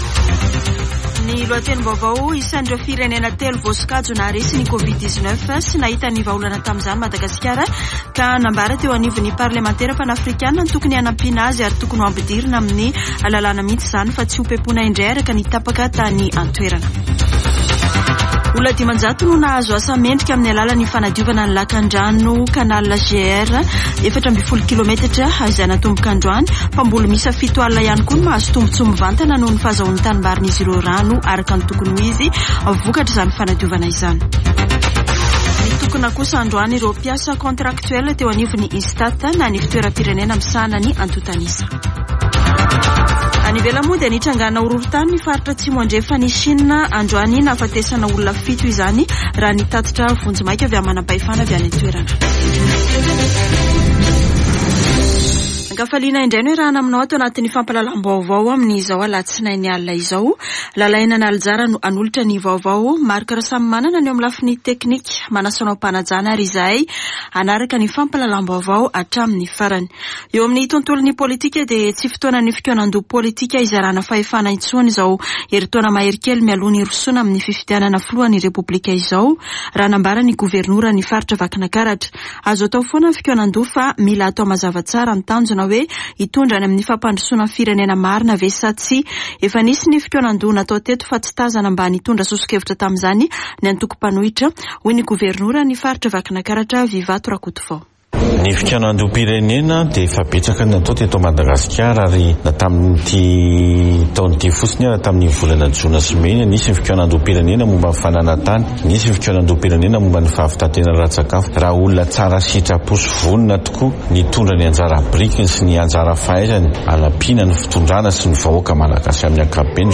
[Vaovao hariva] Alatsinainy 5 septambra 2022